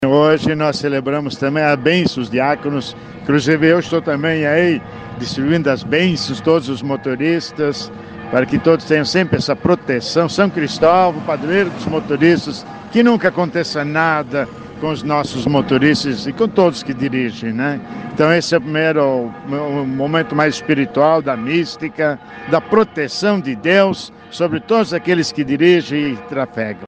Os veículos que passam pela Avenida Tiradentes, em frente à Catedral, estão recebendo a benção do santo protetor dos viajantes.
O arcebispo de Maringá Dom Severino Clasen acompanhava.